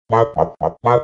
COG_VO_question.ogg